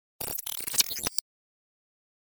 Компьютерная трансформация частиц переходного аудио материала
kompyuternaya_transformaciya_chastic_perehodnogo_audio_materiala_06v.mp3